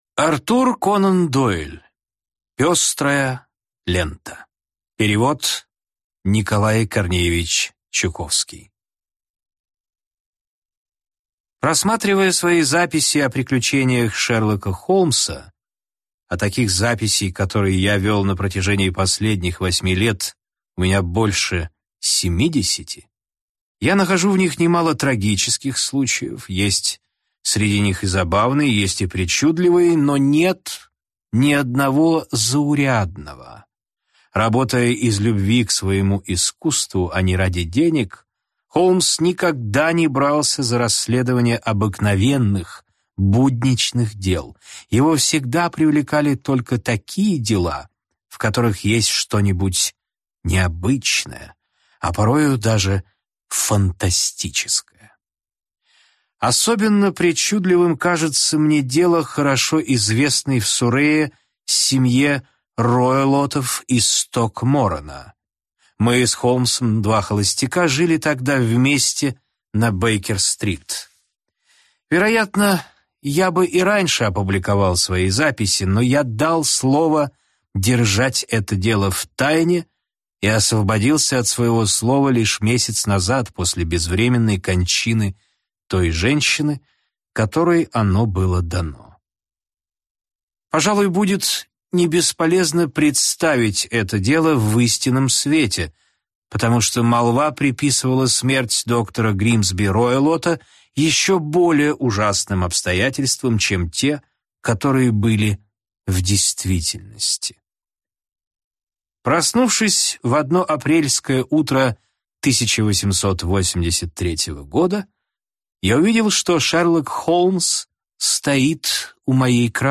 Аудиокнига Пестрая лента | Библиотека аудиокниг